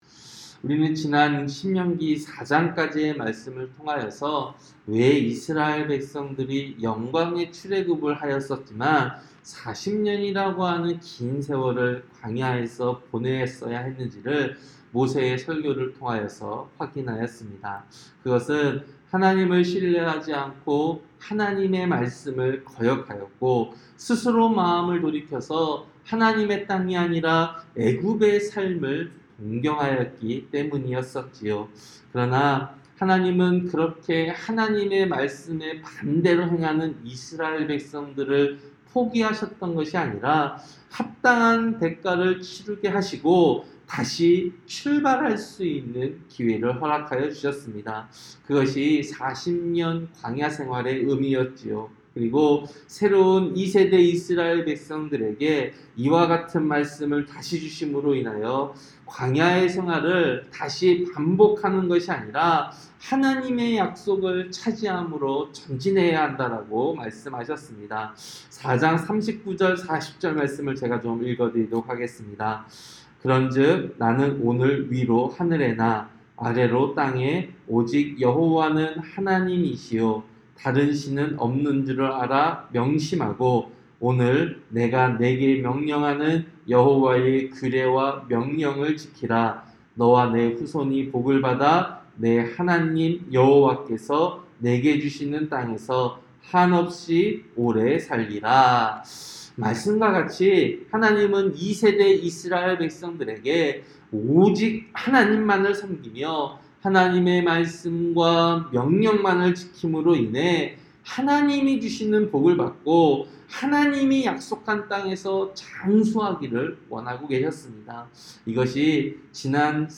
새벽설교-신명기 5장